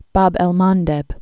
(bäb ĕl mändĕb)